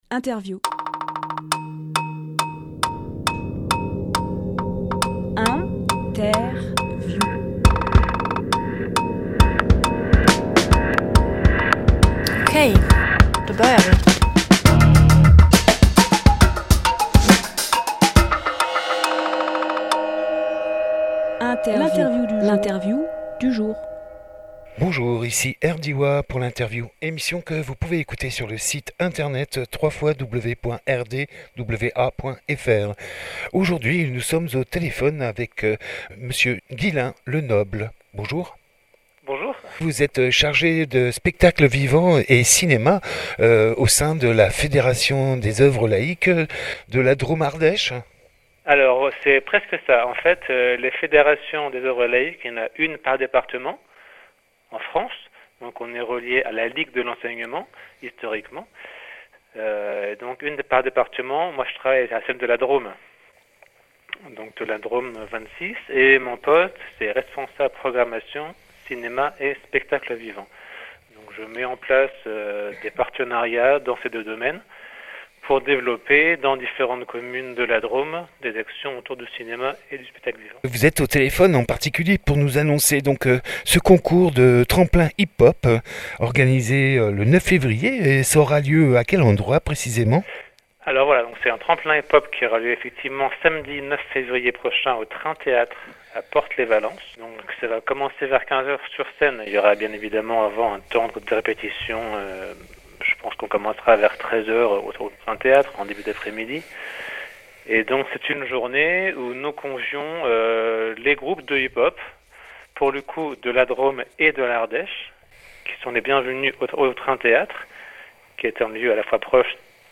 Emission - Interview Tremplin Hip Hop 2019 par la Fédération des Oeuvres Laïques 26 Publié le 7 décembre 2018 Partager sur…
Lieu : Studio RDWA